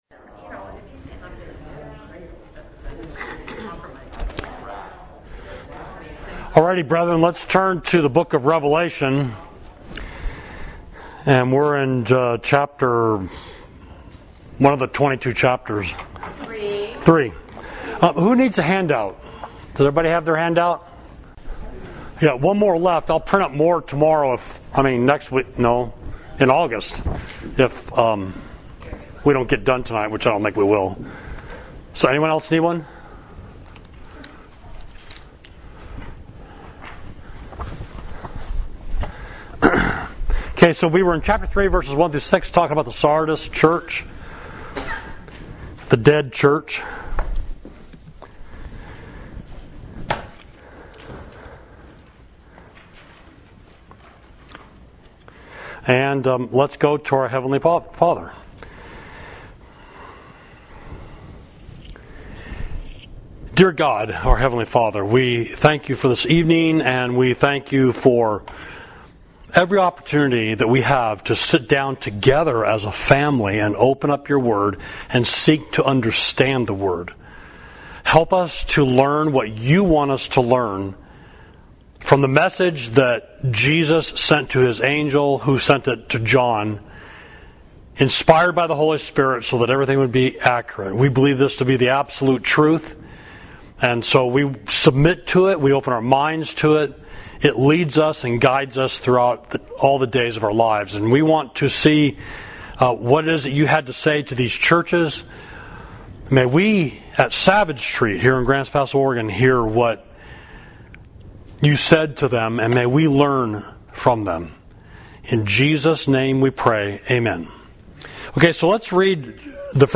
Class: The Seven Churches, Revelation 2–3